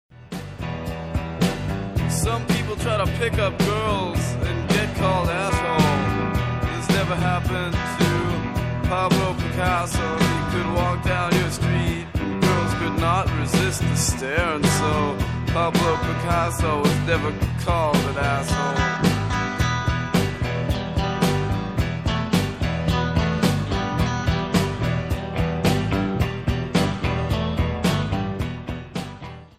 Tesoro Bumbrella Stage
There is a sparse delight to his music (him and his guitar
drums